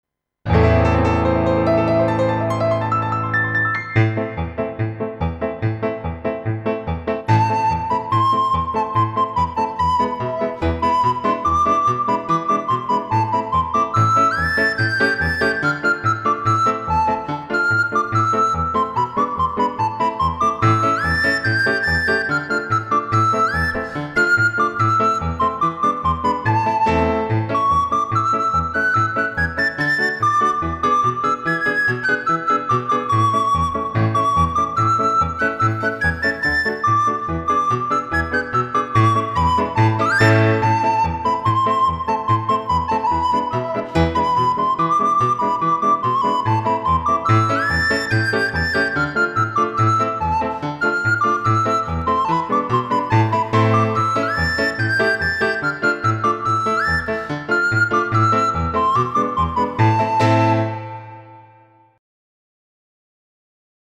خارجی